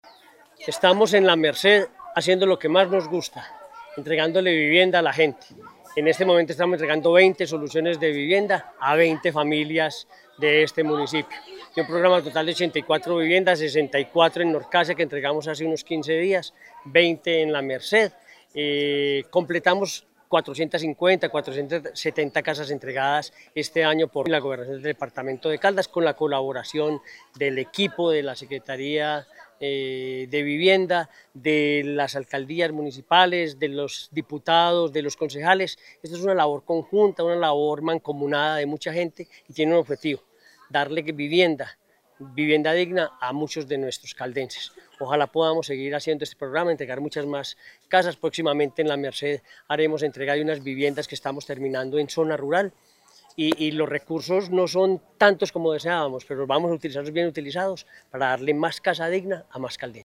Henry Gutiérrez Ángel, Gobernador de Caldas.